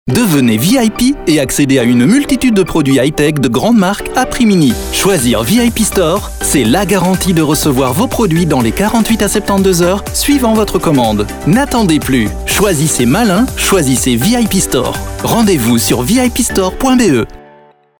spot publicitaire
dynamique , enthousiaste , promo , souriant